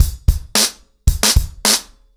BlackMail-110BPM.19.wav